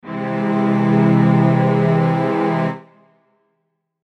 While this, though beautiful in a different way, has tremendous tension? Sounds like the villain (or the cat) is about to pop out and scare you.
The second example is an augmented chord, and yes it sounds like that.